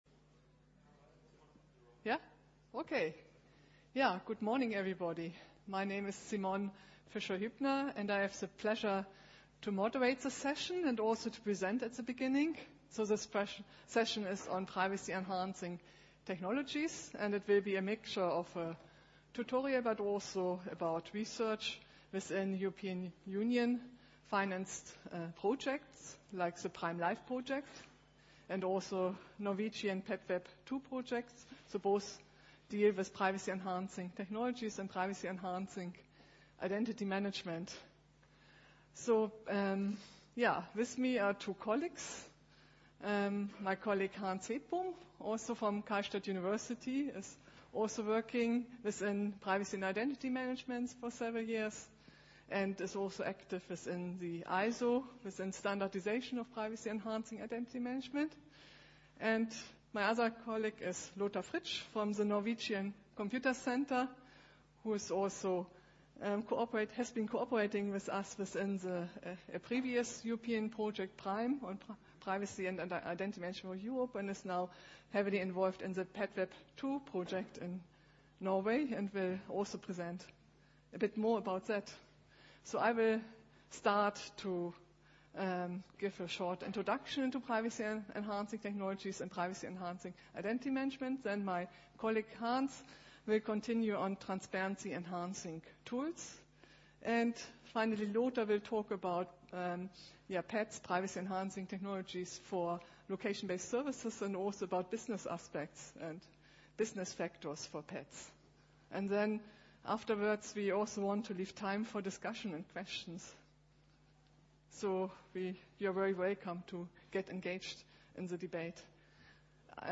In this seminar, the concept of Privacy-enhancing Technologies (PETs) will be presented and examples for basic PET (Mix-nets, anonymous credentials, privacy policies, privacy-enhanced access control, transparency tools) will be given.
Plats: Kongresshall C